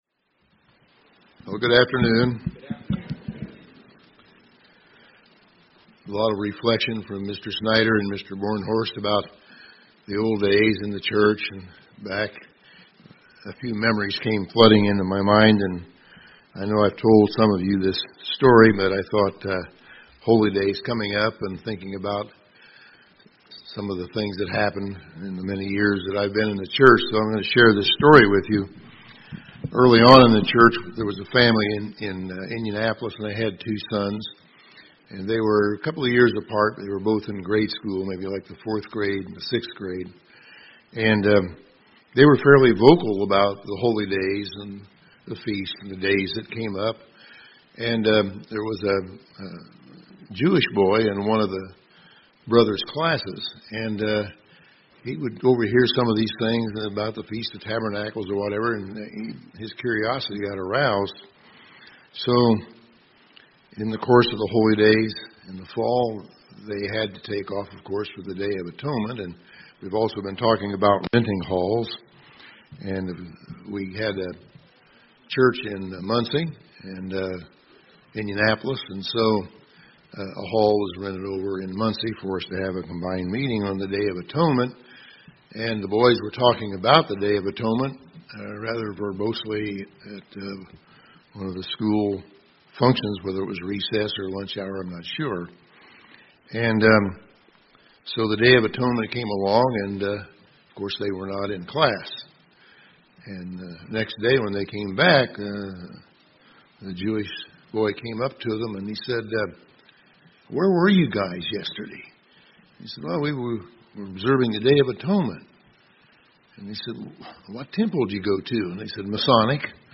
Sermons
Given in Indianapolis, IN